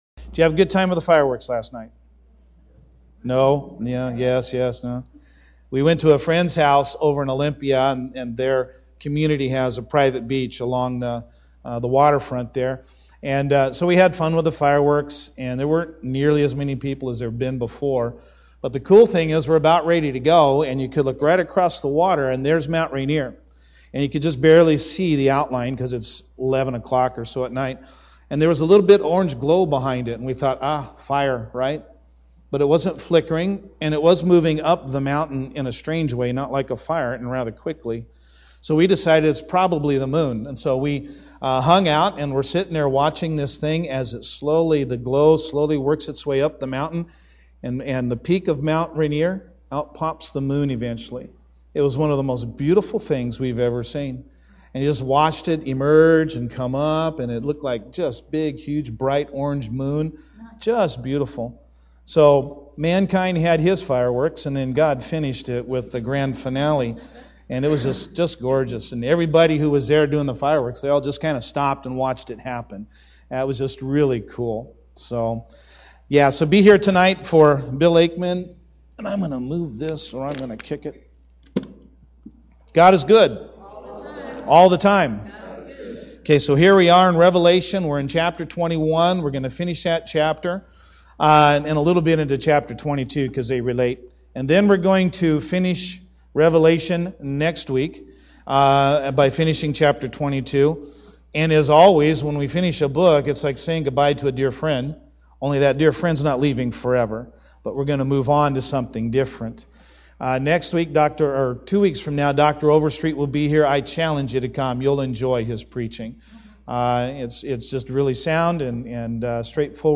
Sermons Archive - Page 3 of 52 - North Mason Bible Church